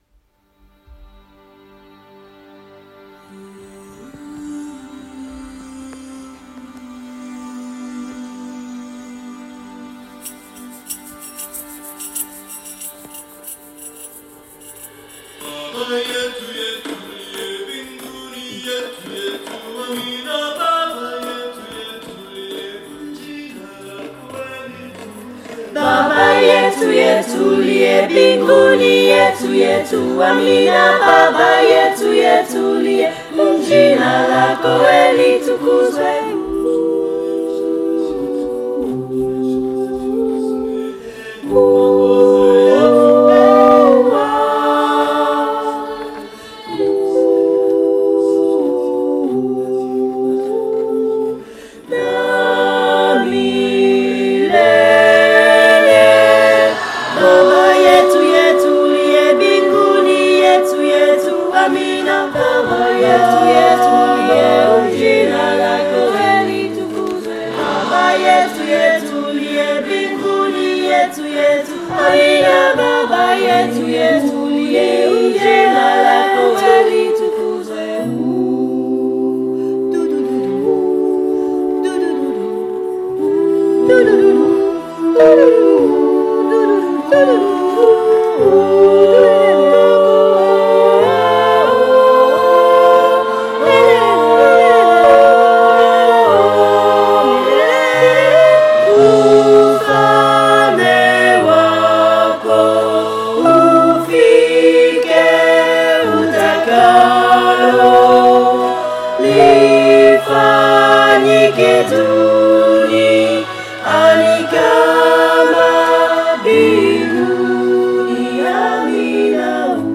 Enregistrements de travail
baba-yetu-tutti-4-voix-TEC.mp3